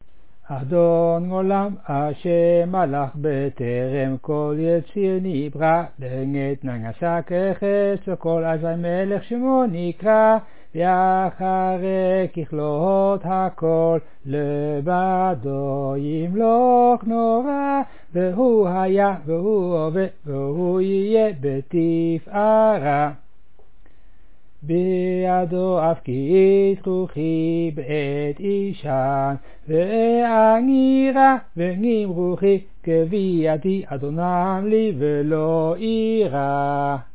fast, when cold in Snoge